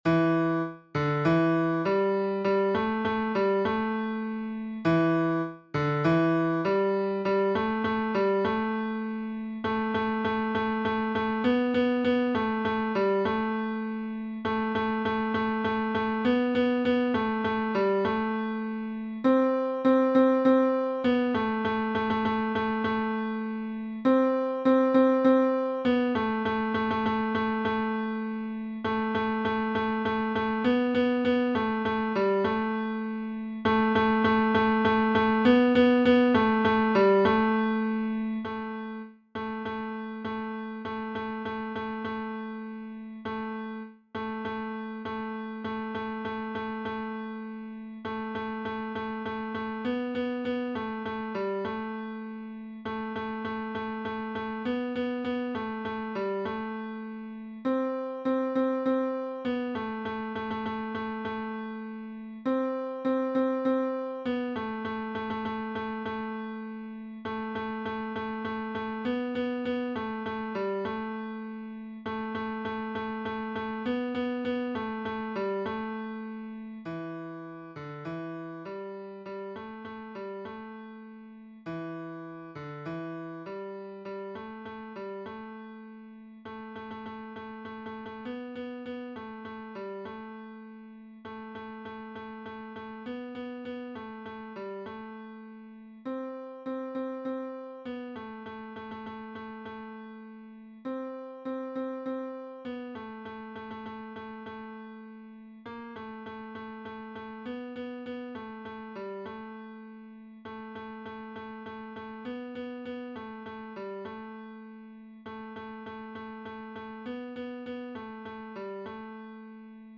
Tenor (version piano